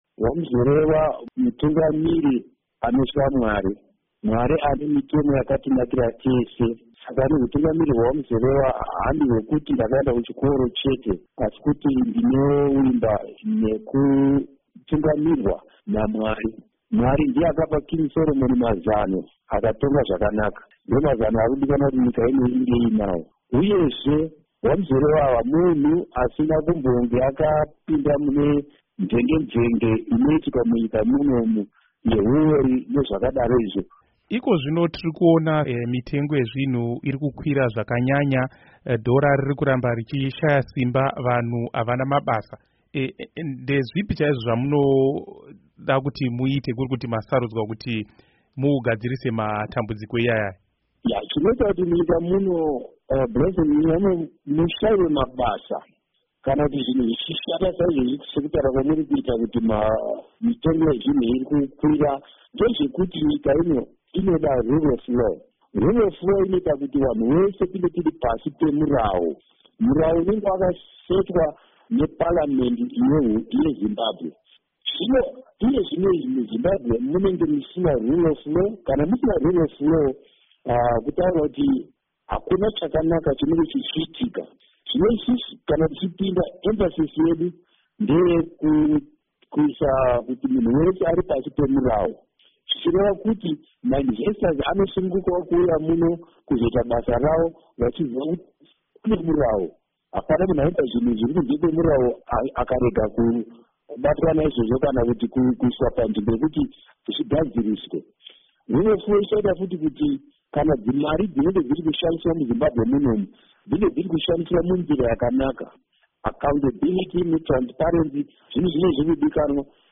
Hurukuro naDoctor Gwinyai Muzorewa